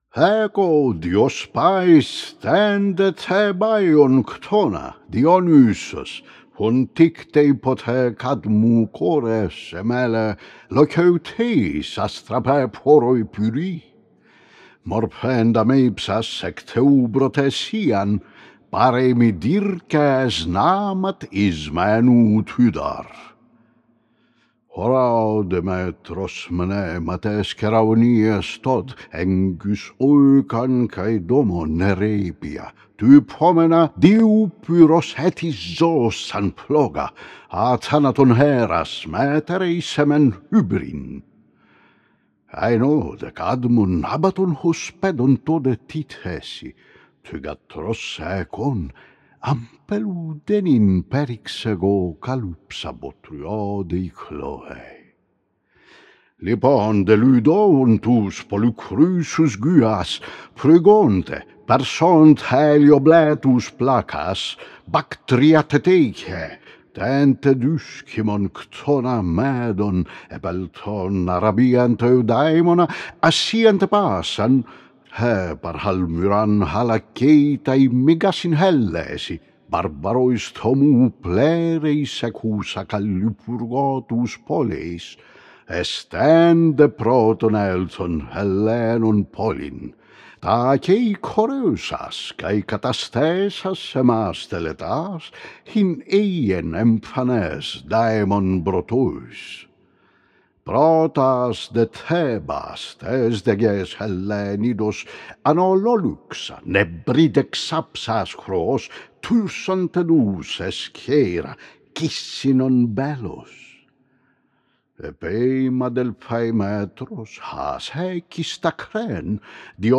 I couldn’t let it but upload a casual recording of the beginning 63 verses of the Bacchae (if it’s too much, be free to delete it, I’ll not be offended ) This is more or less the way I utter the longs. Not all longs are born equal, in duration or otherwise, but this is more a rough trial in style than in anything else. As you’ll hear, I’m not a believer in a steady tempo, however I (try to) keep the relative durations within the cola correct.